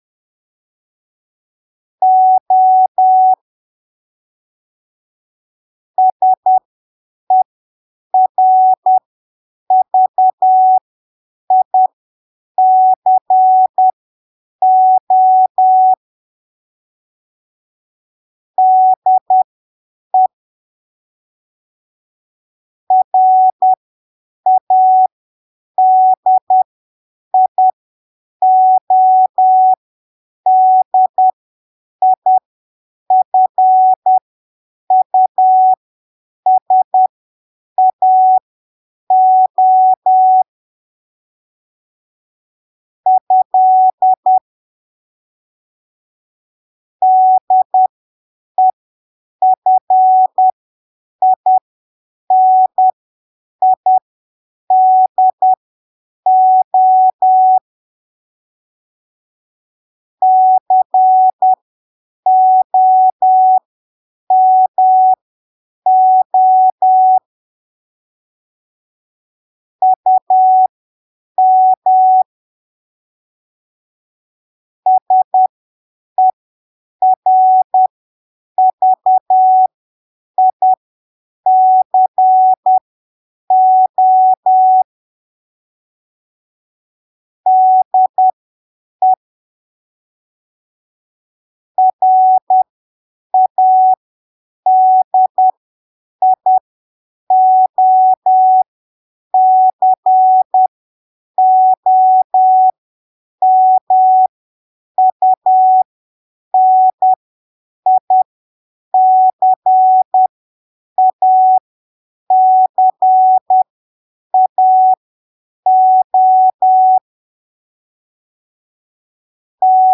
" Textos em CW "
Texto para treinamento em três velocidades 5ppm, 10ppm e 20ppm
texto_01_10ppm.mp3